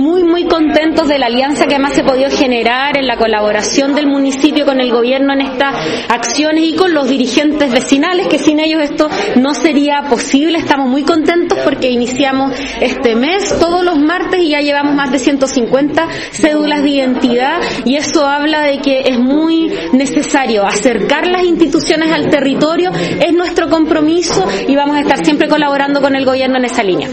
alcaldesa-de-Valdivia1.mp3